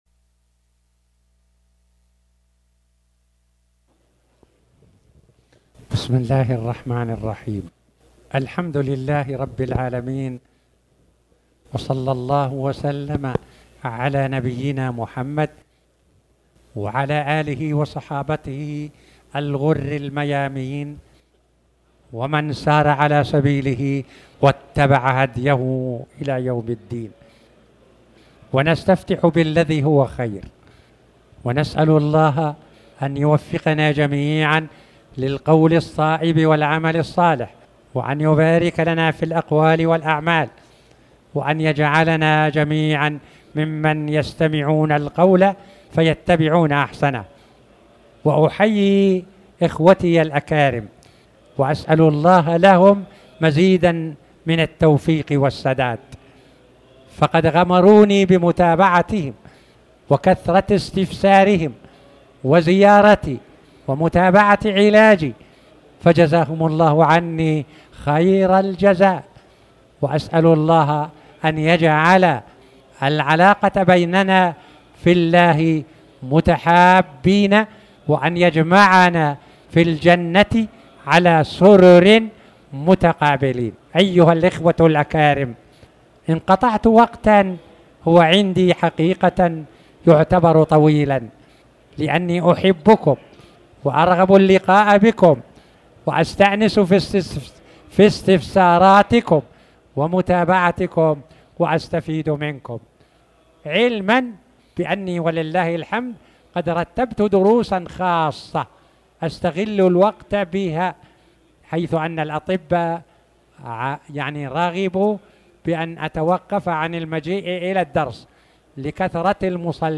تاريخ النشر ٢٥ جمادى الأولى ١٤٣٩ هـ المكان: المسجد الحرام الشيخ